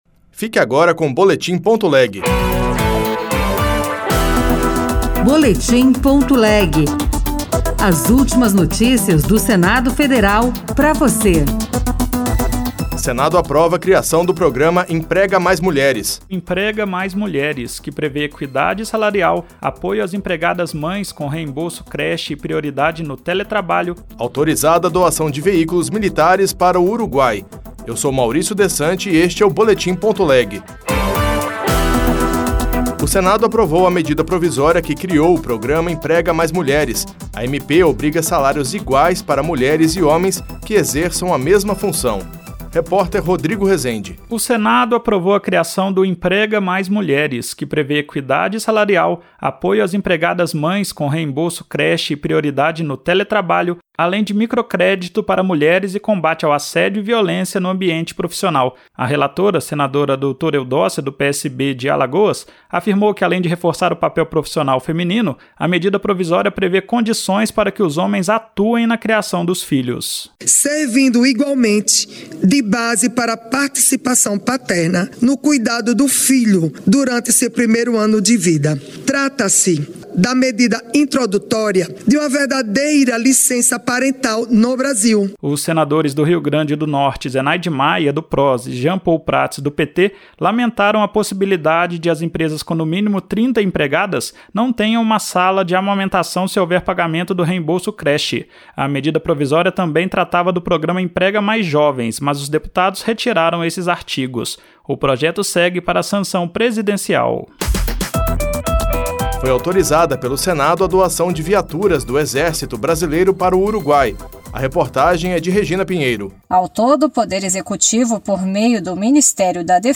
Boletim das 22h